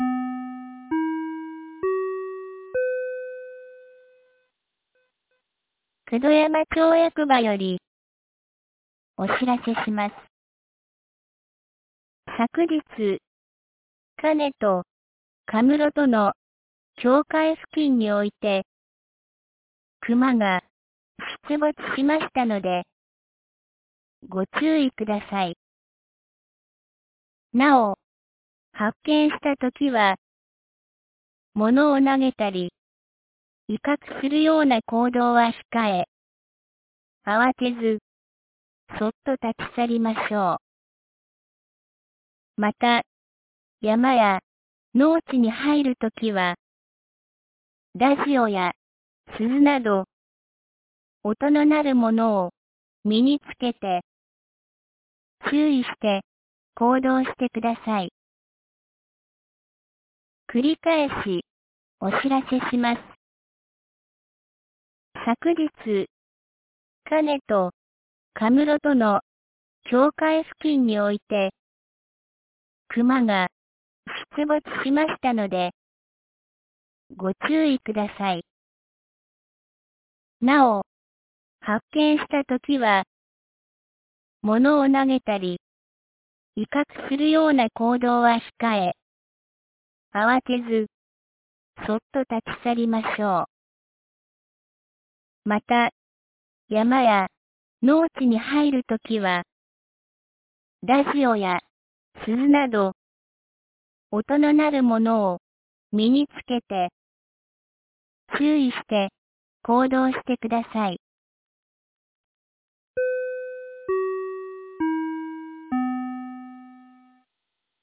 2024年10月17日 13時42分に、九度山町より河根地区へ放送がありました。